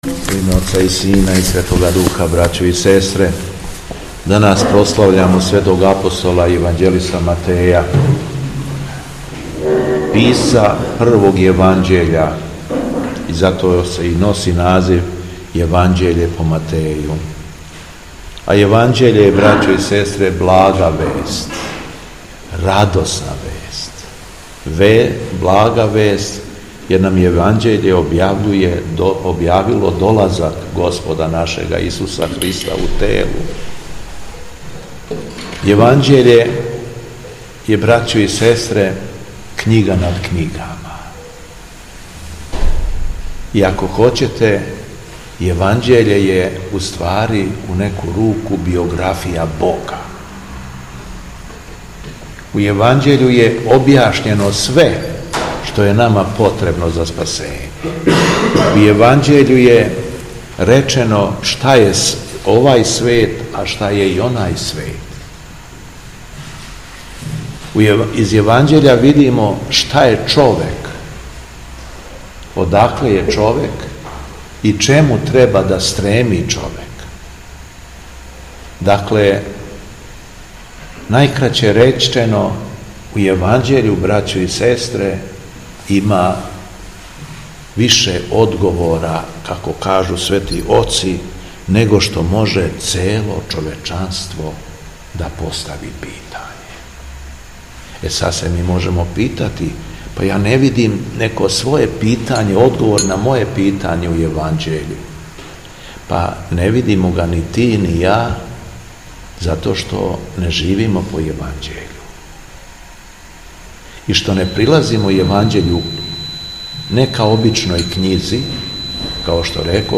Беседа Његовог Преосвештенства Епископа шумадијског г. Јована
Владика Јован је након о прочитаног Јеванђеља одржао беседу: